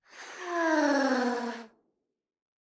Minecraft Version Minecraft Version snapshot Latest Release | Latest Snapshot snapshot / assets / minecraft / sounds / mob / happy_ghast / death.ogg Compare With Compare With Latest Release | Latest Snapshot
death.ogg